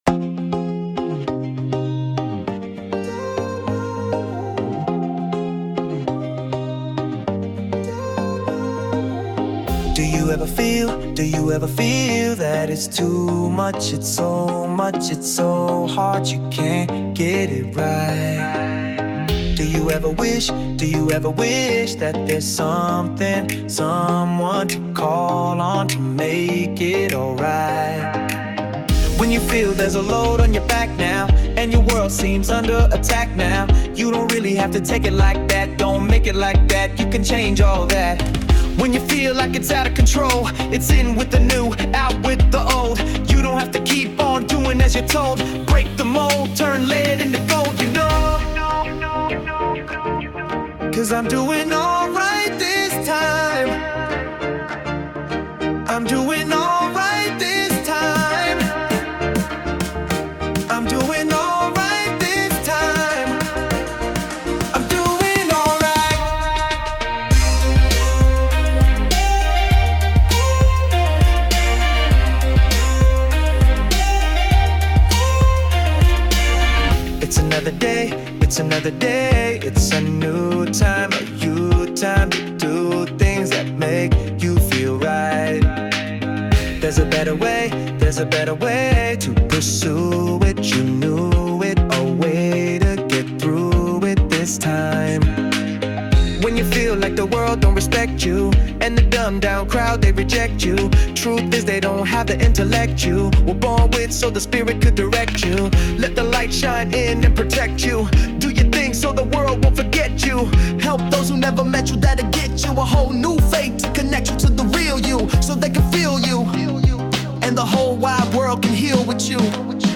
The song is a 70's/80's style pop song
Tagged Under Easy Listening Pop